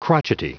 Prononciation audio / Fichier audio de CROTCHETY en anglais
Prononciation du mot crotchety en anglais (fichier audio)